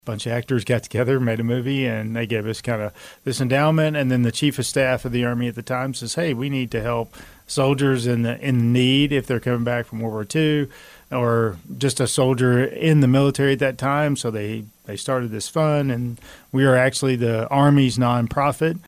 The Manhattan Area Chamber of Commerce held its monthly military relations luncheon on Friday with its featured speaker being retired Sergeant Major of the Army Michael Tony Grinston who was recently appointed the CEO of Army Emergency Reserve.